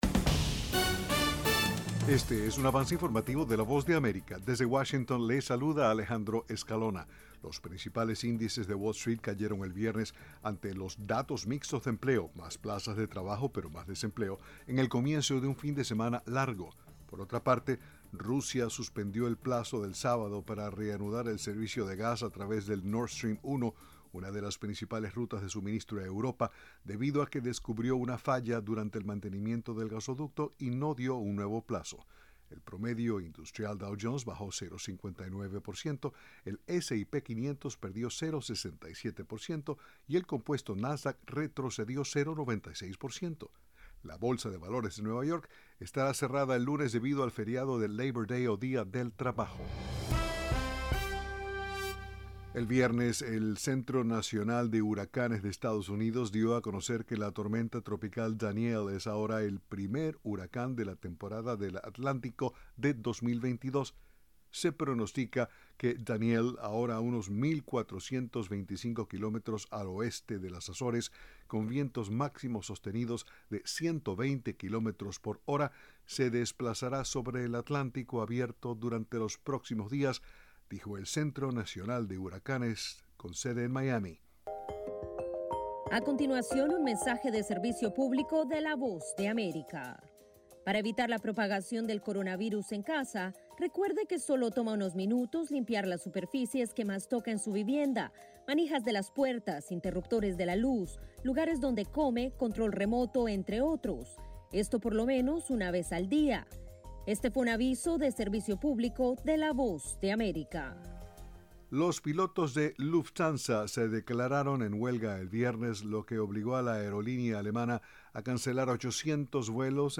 Este es un avance informativo presentado por la Voz de América en Washington.